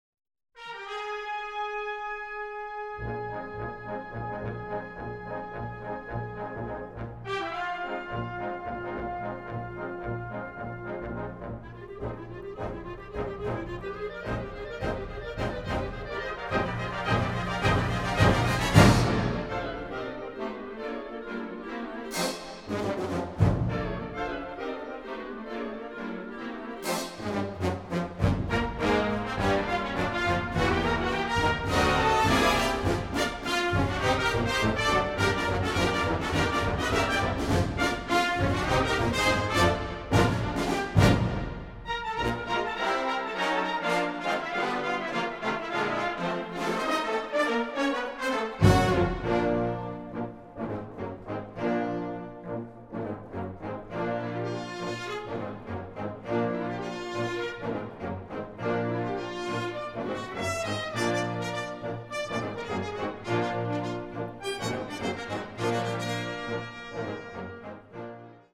Kategorie Blasorchester/HaFaBra